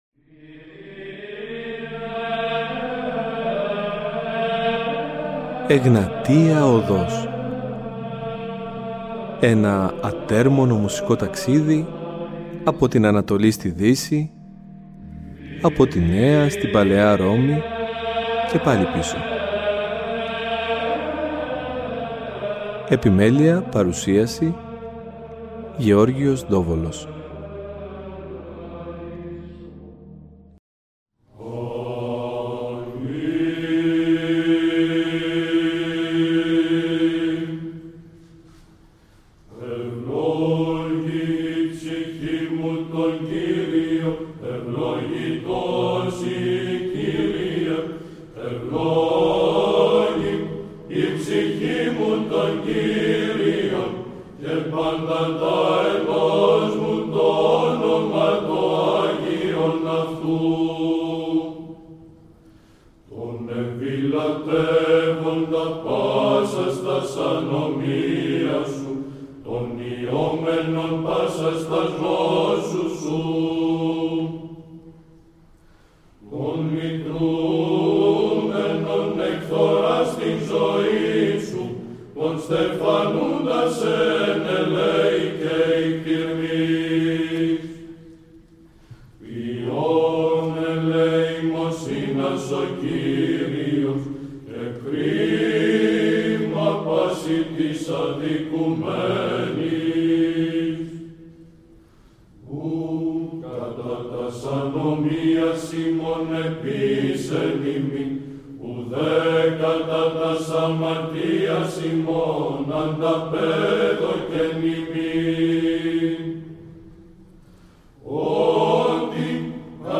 Αυτό το Σαββατοκύριακο ασχολούμαστε με τους Ιερούς Κανόνες που κατά καιρούς εξέδωσε η Εκκλησία μέσα στους οποίους αποκρυσταλλώνεται η εικόνα του ιεροψάλτη σύμφωνα με τους Πατέρες και τα κελεύσματα του χριστιανισμού. Θα ακούσουμε επίσης ύμνους από το Δ’ Διεθνές μουσικολογικό συνέδριο που πραγματοποιήθηκε το 2009 με τίτλο «Ψάλατε Συνετώς τω Θεώ», τίτλος που αποτέλεσε και την πηγή έμπνευσης αυτών των εκπομπών.